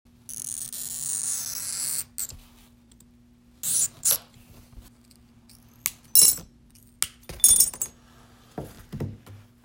A new segment of the "Glass Break 1" audio file, different than "b" sound. No further effects were added. This sound is correlated with the letter "d" on the computer keyboard.
Edited with and exported from Abletone Live.